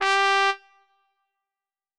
Cow_Life_Sim_RPG/Sounds/SFX/Instruments/Trumpets/doot5.wav at a9e1ed9dddb18b7dccd3758fbc9ca9706f824ea5
doot5.wav